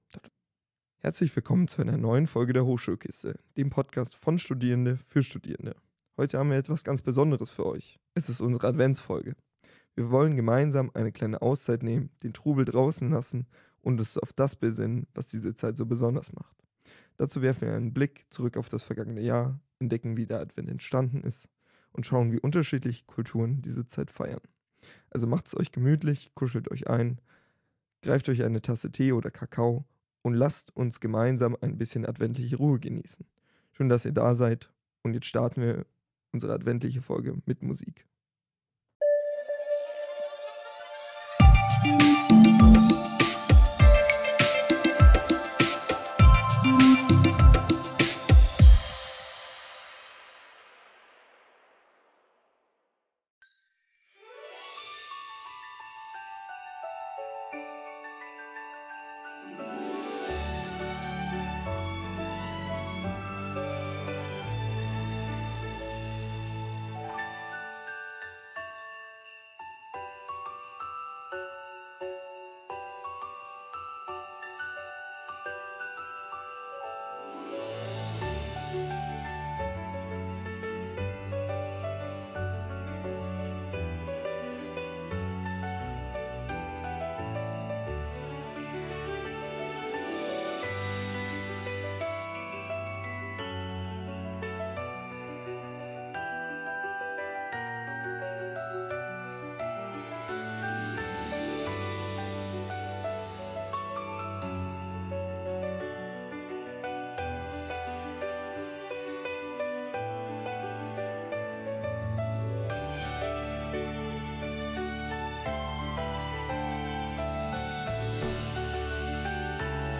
Beschreibung vor 4 Monaten In dieser Podcast-Folge geht es um Weihnachten, Besinnung und Bräuche aus aller Welt. Gastgeber und Gäste sprechen darüber, wie Weihnachten in Augsburg gefeiert wird, welche traditionellen Aktivitäten und Veranstaltungen es gibt und wie man die Adventszeit vor Ort besonders genießen kann.
Außerdem hört man eine Geschichte, die zur Reflexion über das vergangene Jahr anregt.